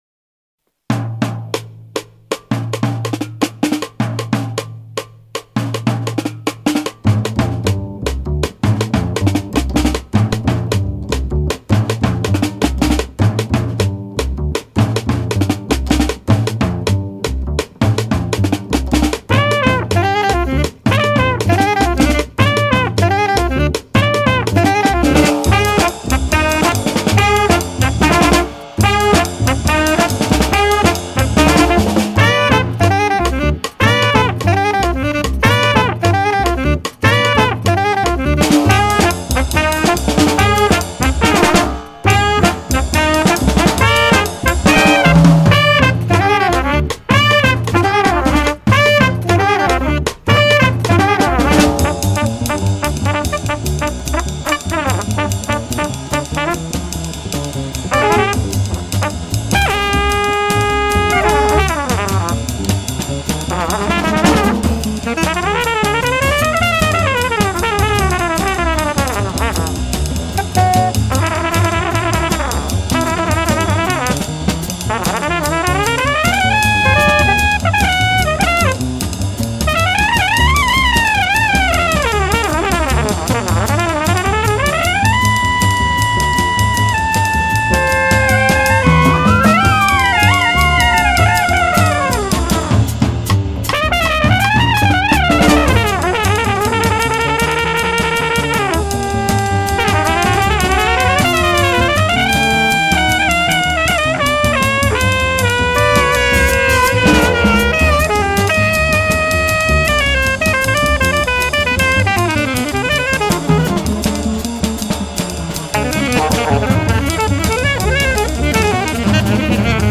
Trumpet, Low Brass, Conch, and Didgeridoo
Woodwinds
Bass
FILE: Jazz